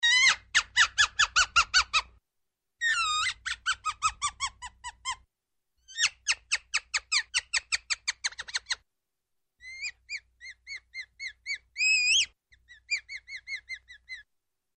суслик кричит